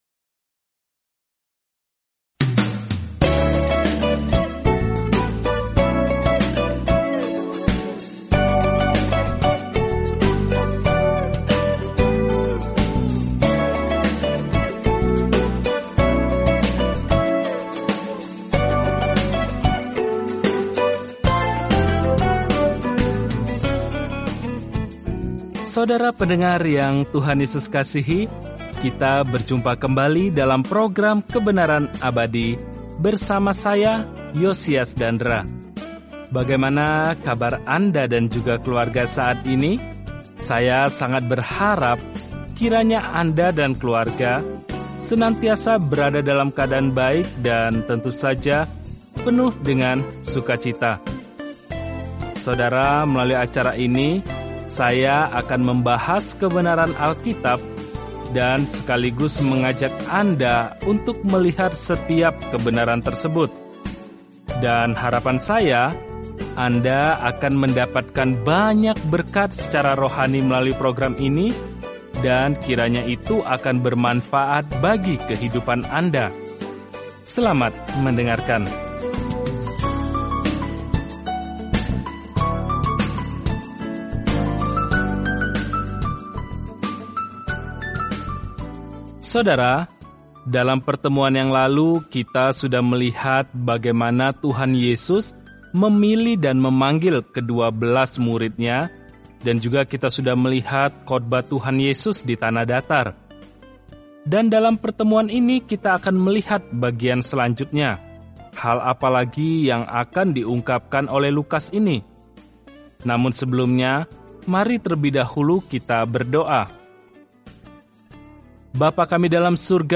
Firman Tuhan, Alkitab Lukas 7 Hari 9 Mulai Rencana ini Hari 11 Tentang Rencana ini Para saksi mata menginformasikan kabar baik yang diceritakan Lukas tentang kisah Yesus sejak lahir, mati, hingga kebangkitan; Lukas juga menceritakan kembali ajaran-Nya yang mengubah dunia. Telusuri Lukas setiap hari sambil mendengarkan pelajaran audio dan membaca ayat-ayat tertentu dari firman Tuhan.